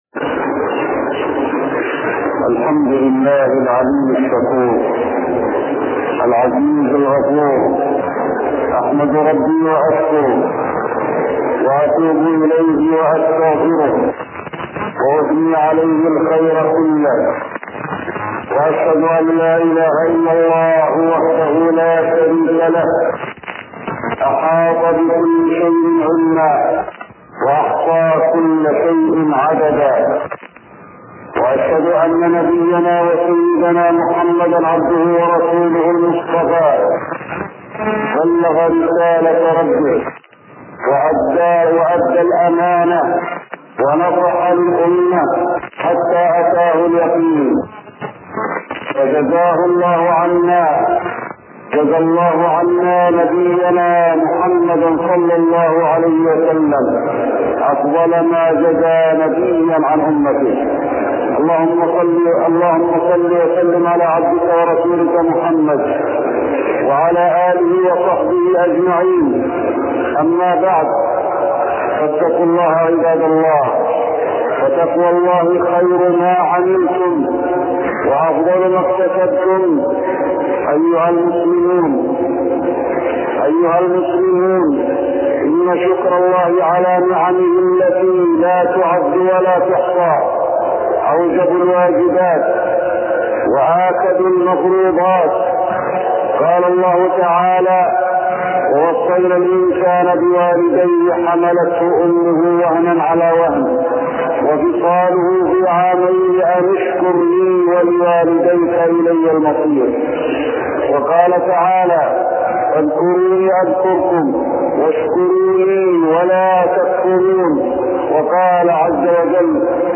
المدينة : شكر النعم (صوت). التصنيف: خطب الجمعة